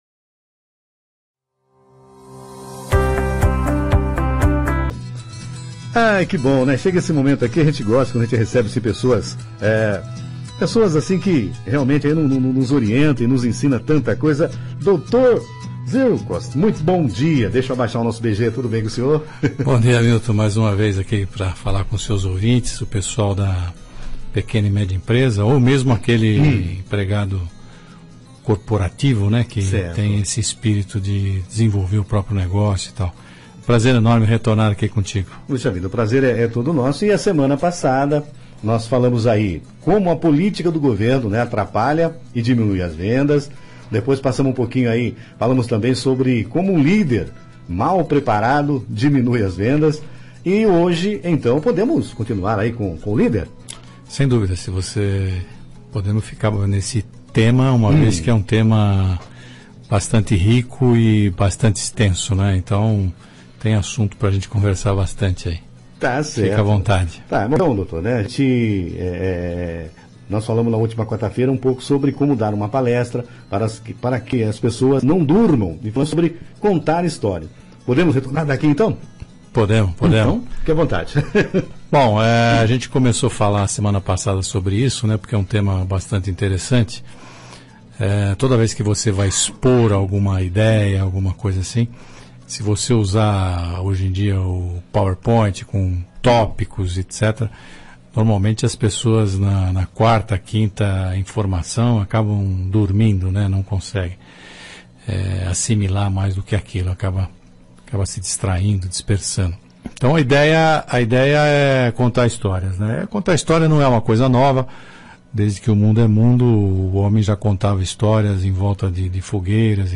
Entrevista à Rádio, Como passar uma mensagem Contando Histórias